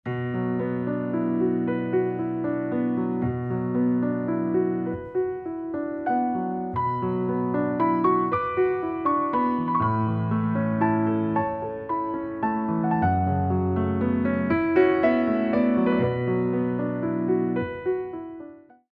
29 Original Piano Pieces for Ballet Class
Ports de Bras
mod. 3/4 - 1:09